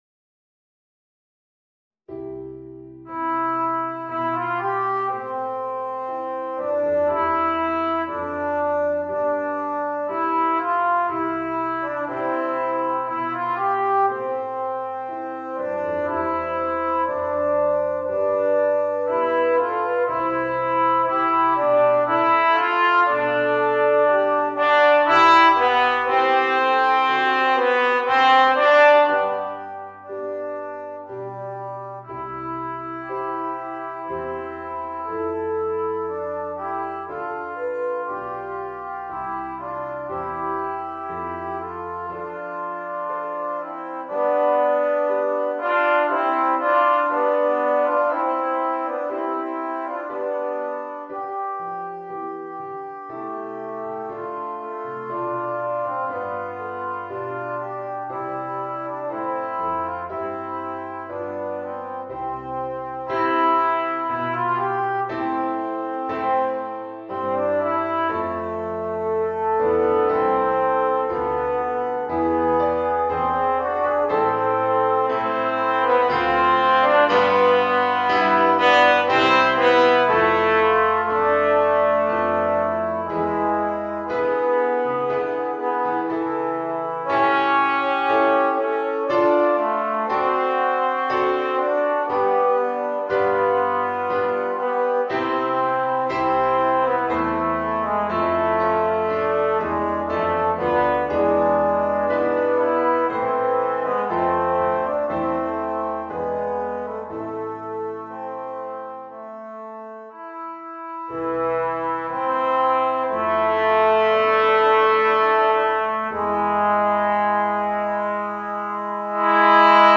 Voicing: 2 Alto Trombones and Piano